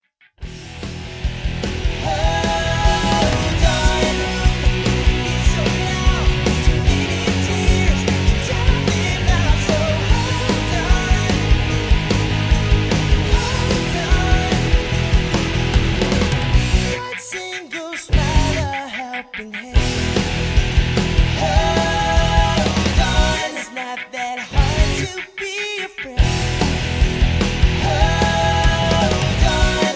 (Key-B) Karaoke MP3 Backing Tracks
Just Plain & Simply "GREAT MUSIC" (No Lyrics).